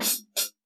Closed Hats
2hatrz.wav